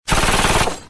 Ametralladora Dispara